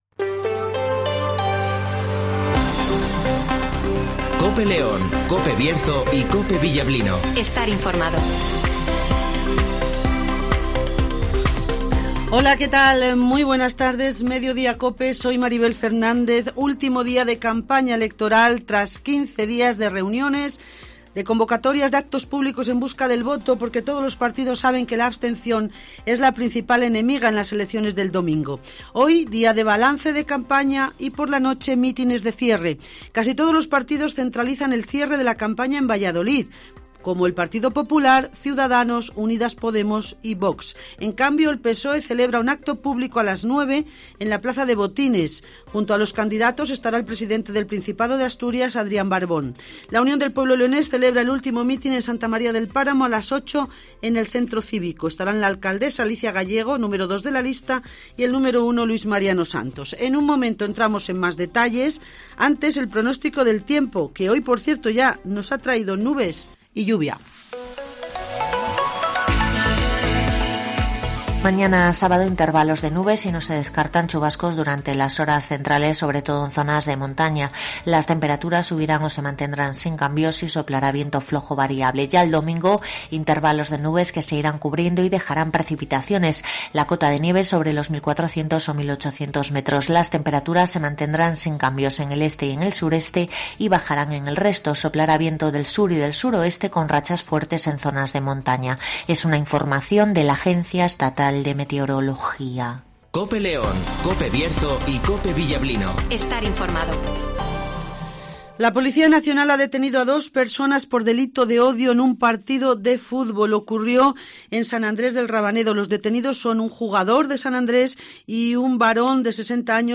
- Francisco Igea ( Candidato a la Junta Cs )
- Camino Cabañas ( Alcaldesa de " San Adrés del Rabanedo " )